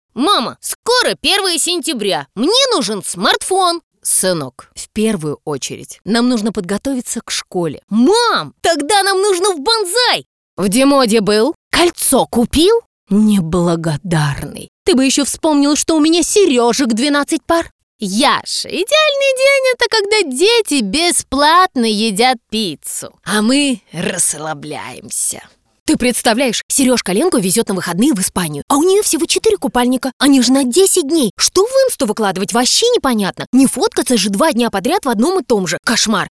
• Голос: Сопрано
• Женский
• Высокий
Игровой текст - Мама и сын